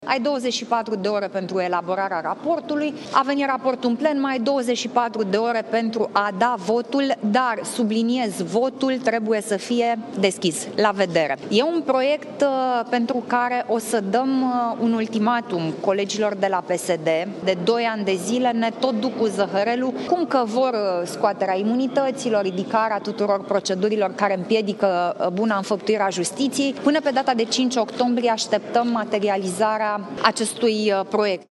”De doi ani de zile ne tot duc cu zăhărelul exprimând în spațiul public cum că vor scoaterea imunităților, ridicarea tuturor procedurilor care împiedică buna înfăptuire a Justiției, dar (…) la PSD diferența între declarația publică și concret, ce se întâmplă la vot, este foarte mare. Până pe 5 octombrie așteptăm materializarea acestui proiect, adică votul dat pe acest proiect de modificare a regulamentului. Dacă în data de 5 octombrie nu se va întâmpla acest lucru, vom discuta orice mijloc de presiune politică a parlamentarilor liberali pentru a putea mișca lucrurile pe mai departe“, a declarat Alina Gorghiu, la finalul ședinței Biroului Politic Național.